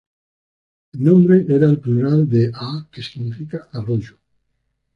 Pronunciado como (IPA)
/pluˈɾal/